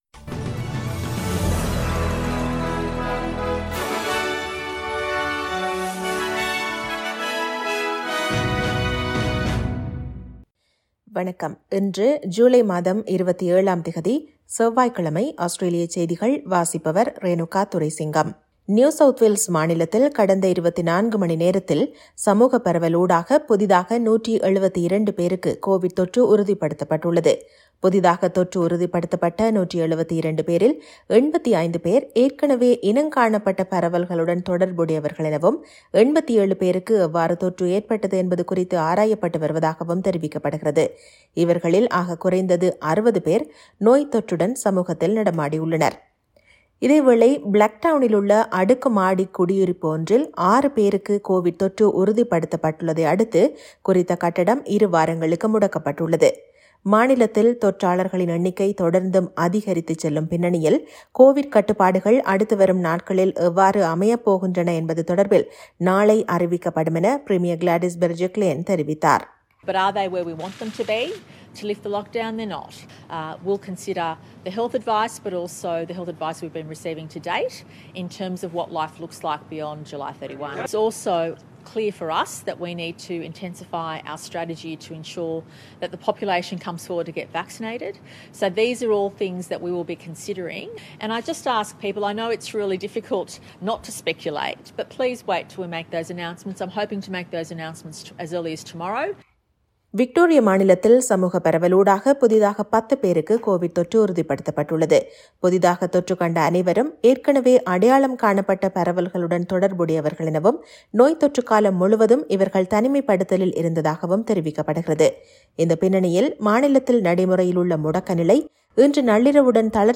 Australian news bulletin for Tuesday 27 July 2021.